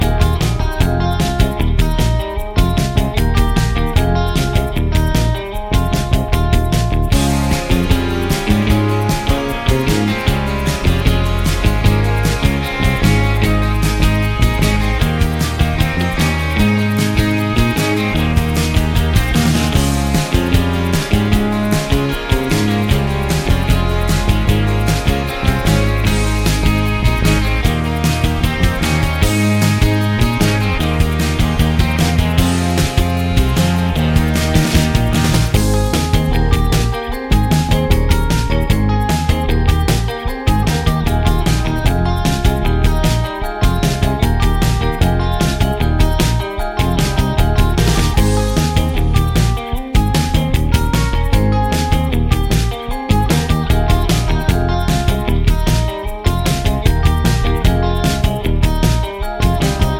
no Backing Vocals Indie / Alternative 4:31 Buy £1.50